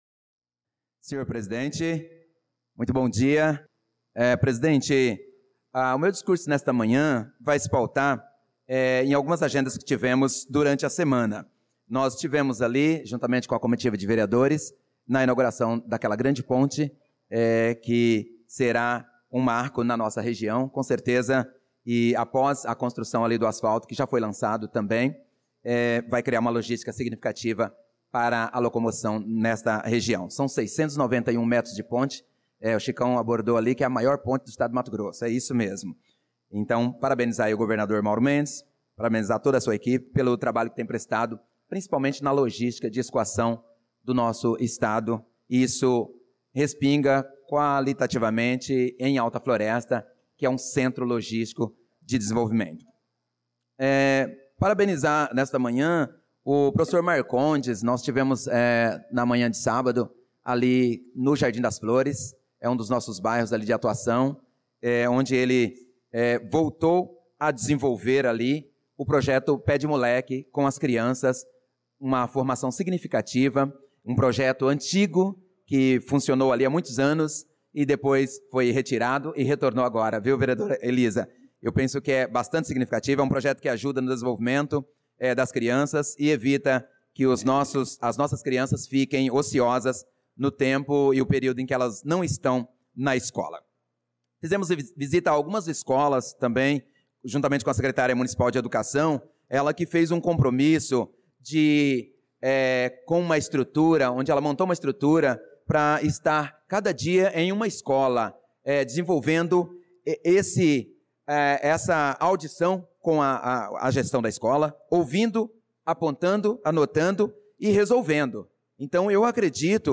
Pronunciamento do vereador Prof. Nilson na Sessão Ordinária do dia 25/02/2025 — Câmara Municipal